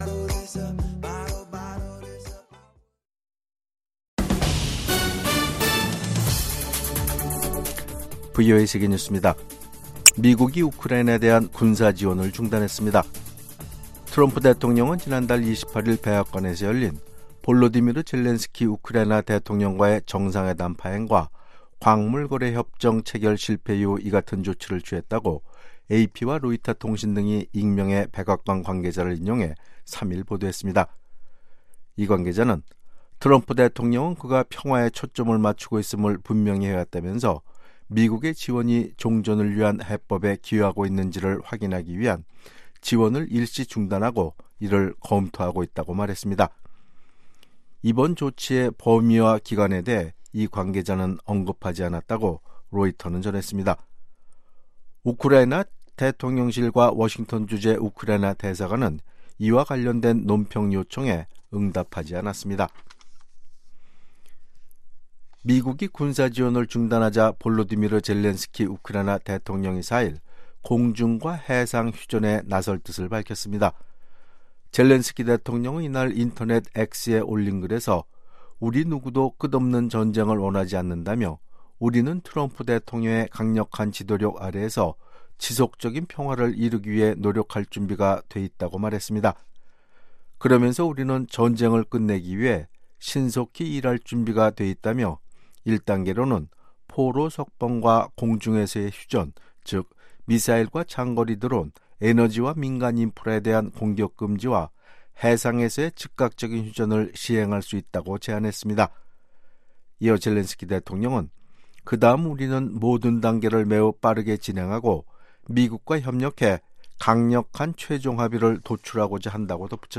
VOA 한국어 아침 뉴스 프로그램 '워싱턴 뉴스 광장'입니다. 북한이 영변 핵 시설 내 원자로 재가동을 시작했으며, 핵연료 재처리를 준비하는 징후가 포착됐다고 국제원자력기구(IAEA)가 밝혔습니다. 도널드 트럼프 미국 대통령이 4일 밤 미국 의회 상하원 합동 회의에서 연설할 예정입니다.